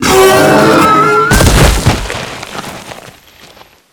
monsterdie.wav